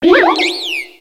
Cri de Munna dans Pokémon X et Y.